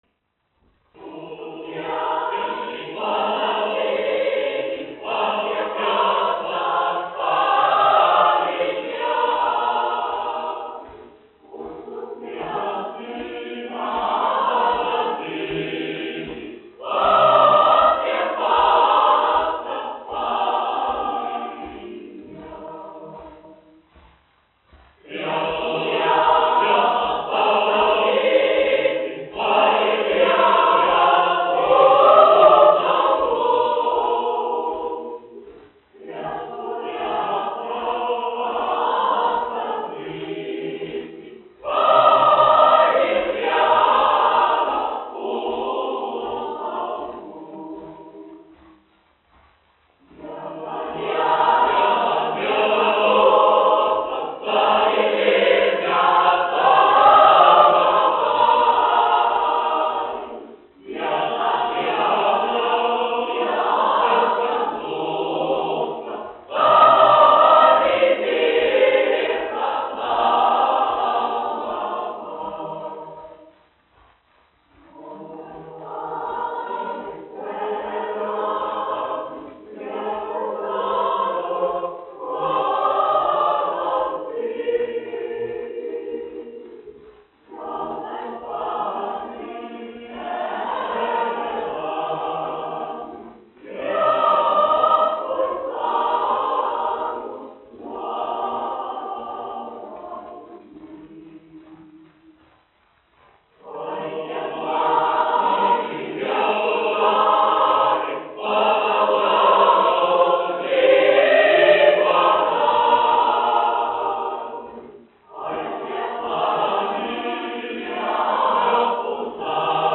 Latvijas Nacionālā opera. Koris, izpildītājs
1 skpl. : analogs, 78 apgr/min, mono ; 25 cm
Latviešu tautasdziesmas
Kori (jauktie)
Skaņuplate